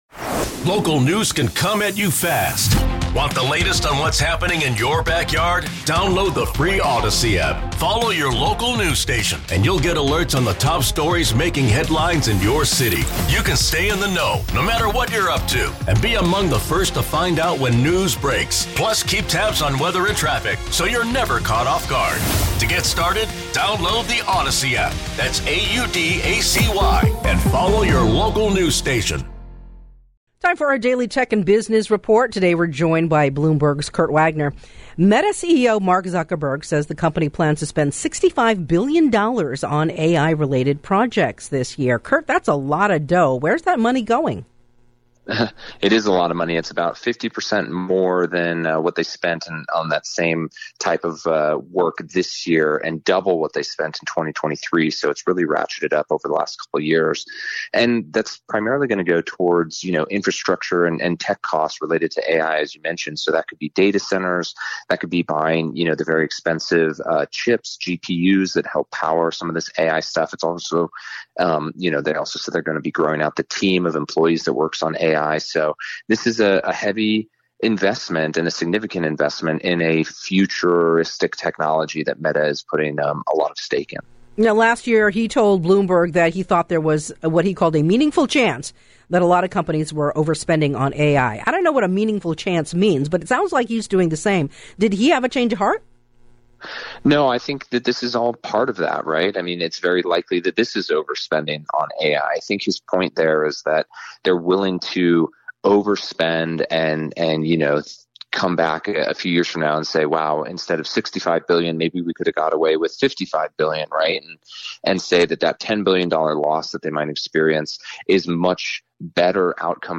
This is KCBS Radio's daily Tech and Business Report.